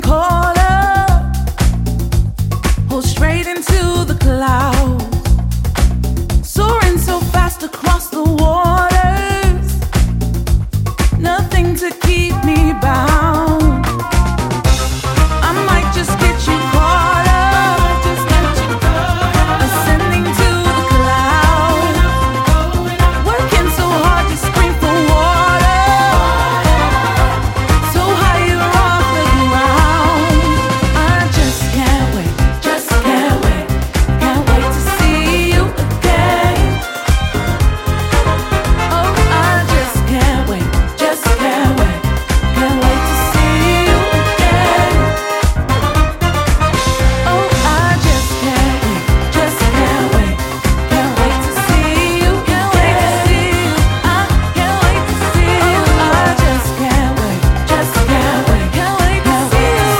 ジャンル(スタイル) SOULFUL HOUSE / AFRO HOUSE